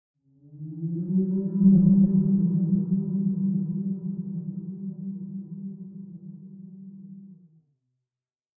File:Sfx creature glowwhale call 05.ogg - Subnautica Wiki
Sfx_creature_glowwhale_call_05.ogg